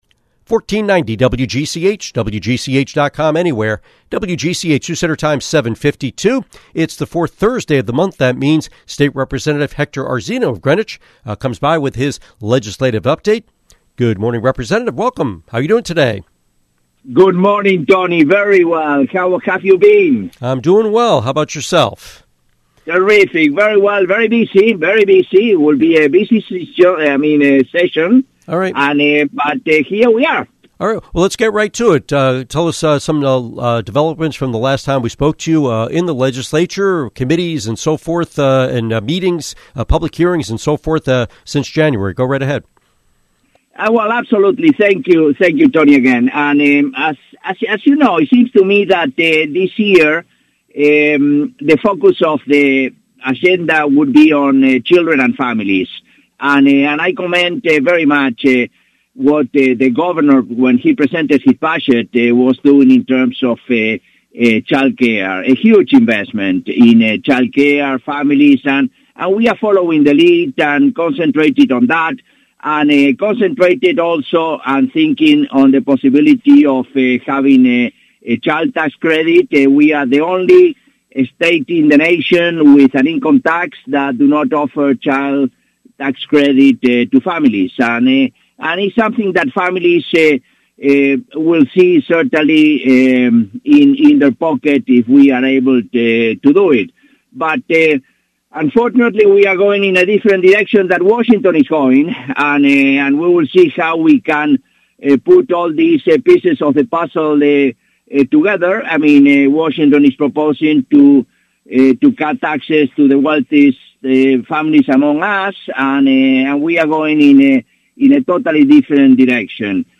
Interview with State Representative Hector Arzeno